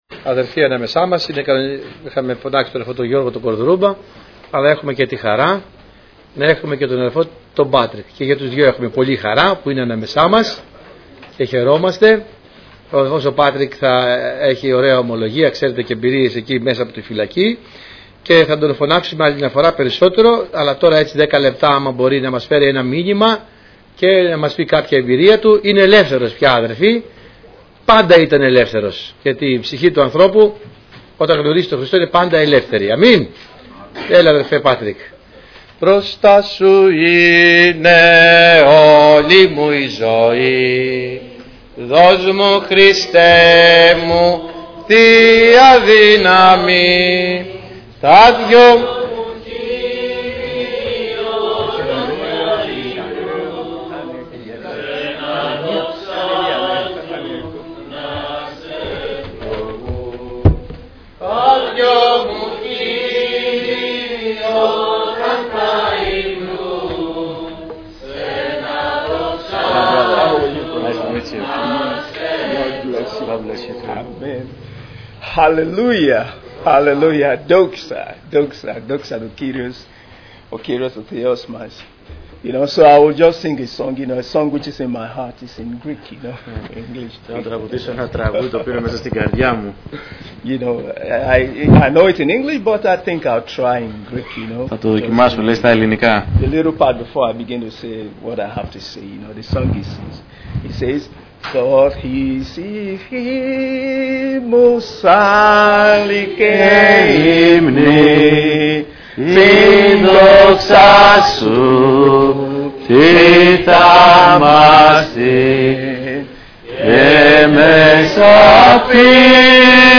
Στο Αρχείο Κηρυγμάτων θα βρείτε τα τελευταία Κηρύγματα, Μαθήματα , Μηνύματα Ευαγγελίου που έγιναν στην Ελευθέρα Αποστολική Εκκλησία Πεντηκοστής Αγίας Παρασκευής
Ομιλητής: Διάφοροι Ομιλητές Λεπτομέρειες Σειρά: Κηρύγματα Ημερομηνία